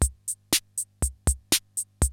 CR-68 LOOPS2 5.wav